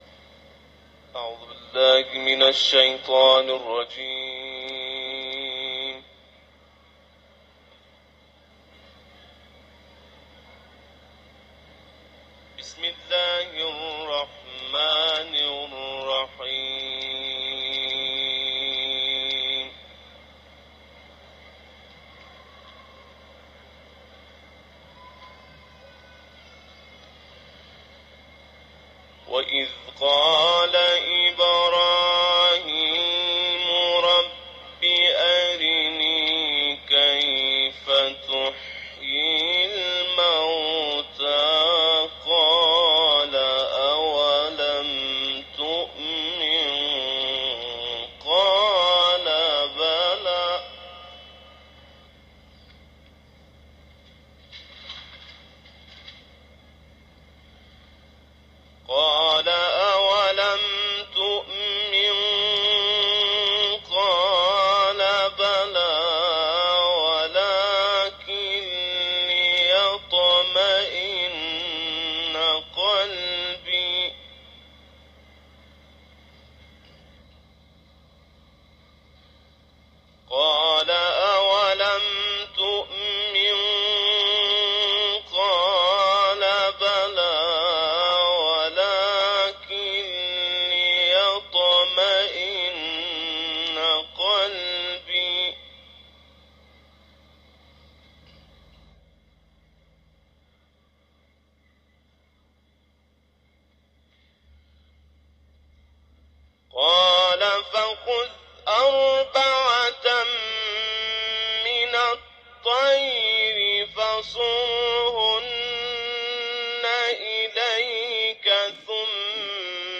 صوت تلاوت